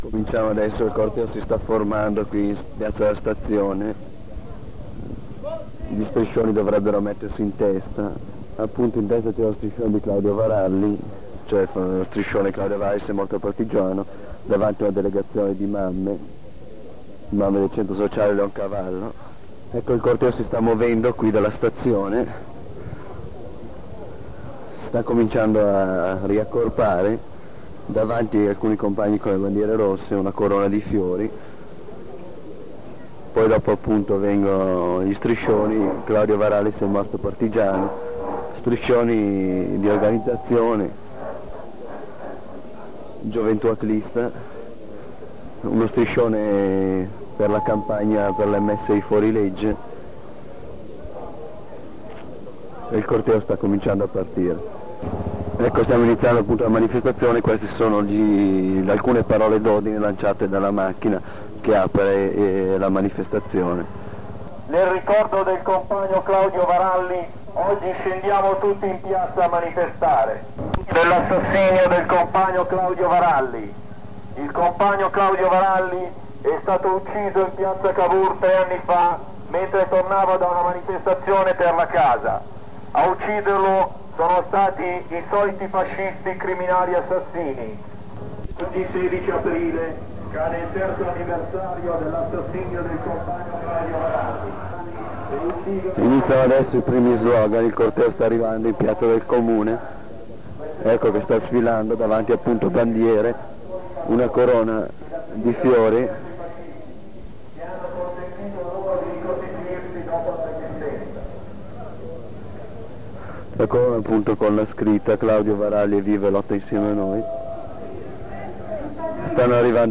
All' allegato (6) radiocronaca del 16 Aprile 1978 effettuata dall' emittente in occasione della manifestazione per il terzo anniversario della morte di Claudio Varalli, giovanissima vittima bollatese degli anni di piombo.